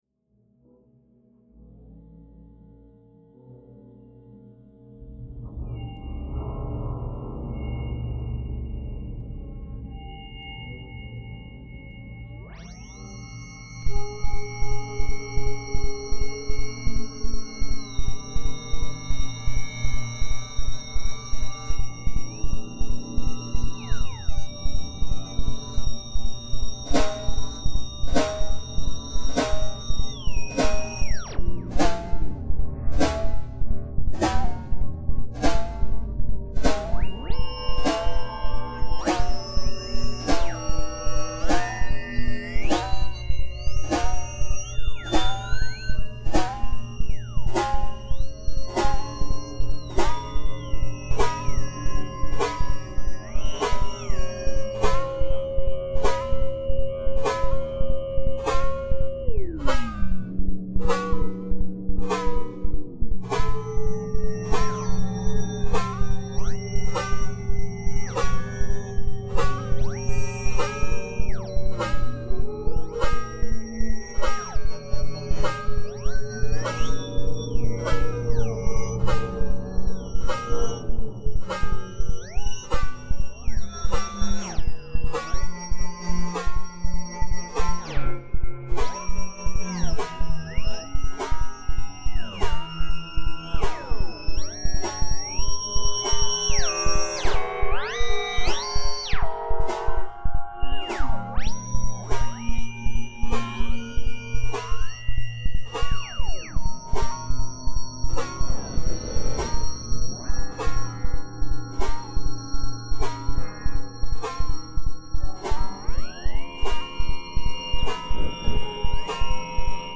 The orchestral animal, that’s what it could be associated with. The choral attitude, the enthusiast-ensemble-mode, the orthodox expressionism, infiltrated among the classical rows: above all, the historicised Must of Perpetrating attentions.